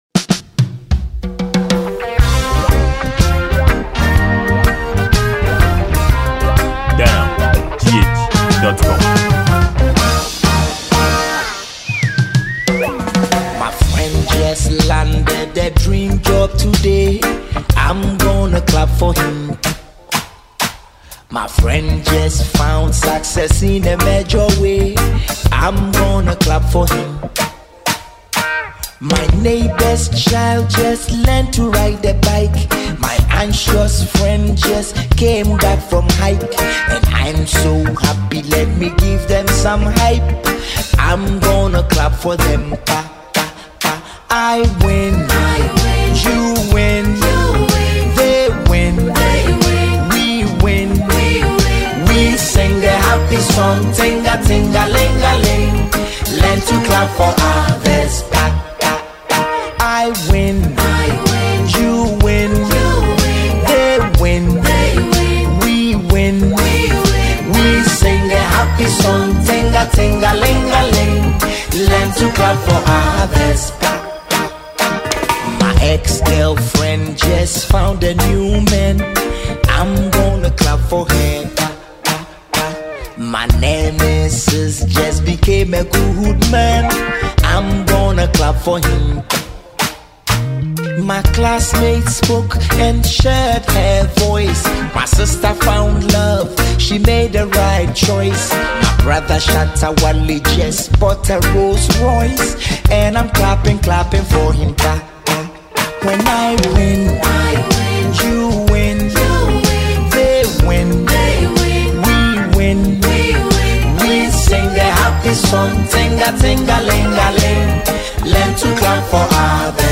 Ghanaian top-class highlife rapper